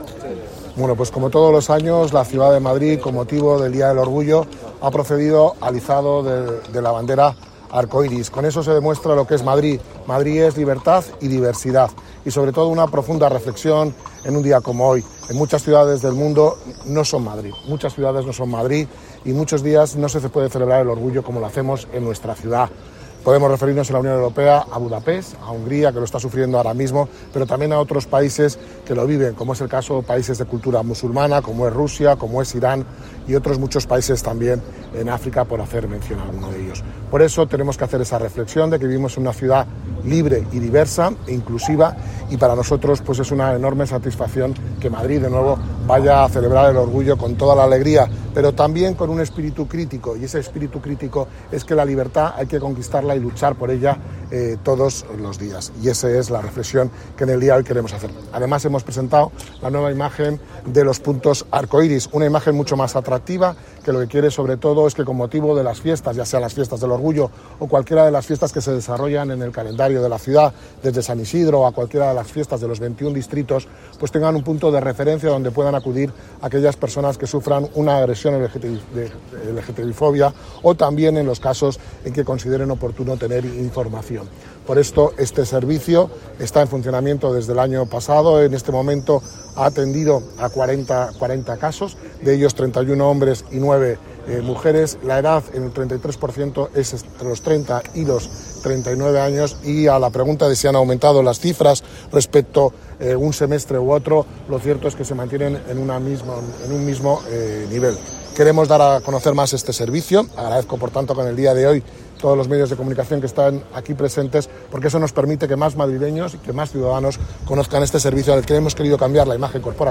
El delegado de Políticas Sociales, Familia e Igualdad, José Fernández, ha presentado hoy el nuevo diseño distintivo de los Puntos Arcoíris que el Ayuntamiento de Madrid instala en fiestas municipales y eventos en la ciudad para sensibilizar contra la lgtbifobia y atender a posibles víctimas. La nueva imagen, más reconocible para facilitar una mejor identificación de estos espacios, se ha dado a conocer en el acto de izado de la bandera LGTBI que el propio Fernández y otros miembros del Gobierno municipal han realizado en la plaza de Pedro Zerolo junto a la asociación Diversa Global con motivo del Día Internacional del Orgullo LGTBI, que se conmemora cada 28 de junio.